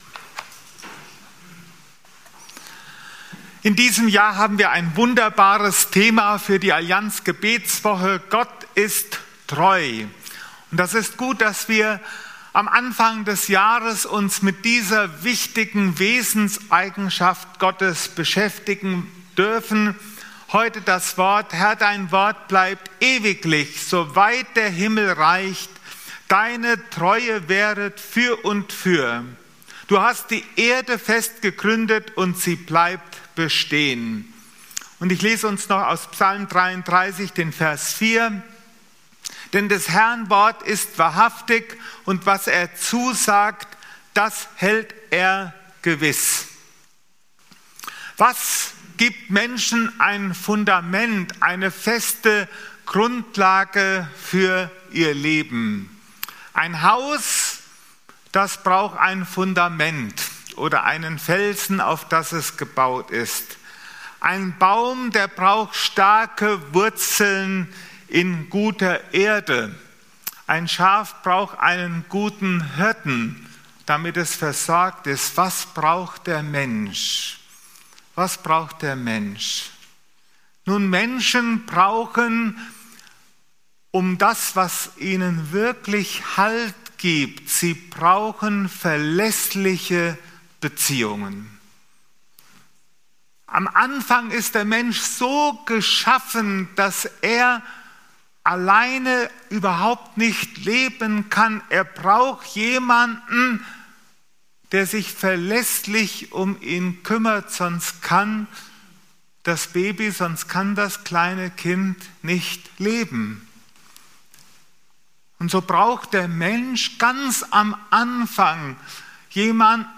Predigten - FeG Steinbach Podcast